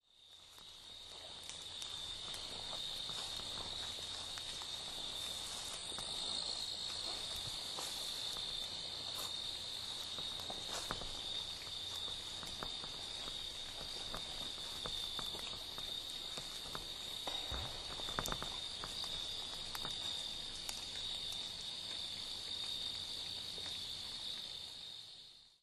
3D-printed parabolic microphone system with recorder, headphones, and powered lapel mic
I also went exploring behind the pond and along the edge of the creek that borders the property, hoping to hear something interesting. If you listen carefully to the file below, you’ll hear a faint ka-thunk in there.
That’s clearly a beaver diving – heard it too many times before. This one was behind me, however, directly opposite the mic direction, otherwise it should have been many times louder.